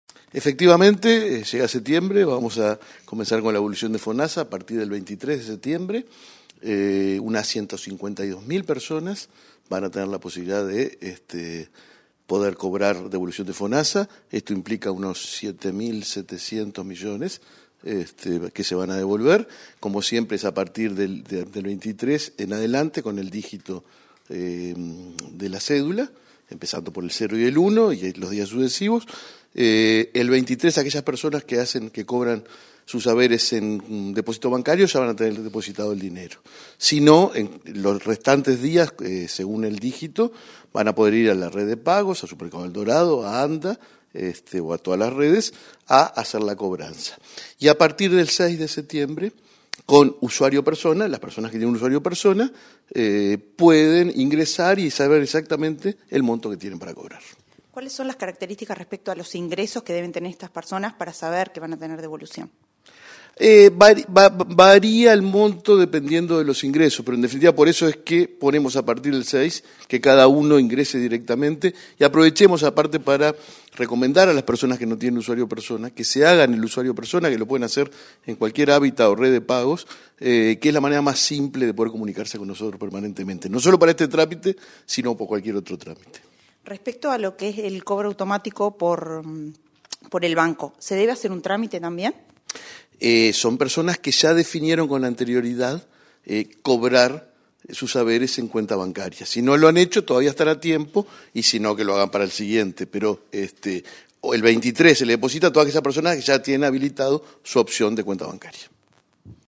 Entrevista al presidente del BPS, Alfredo Cabrera